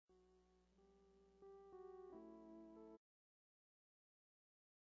Posted in Sermons on 02.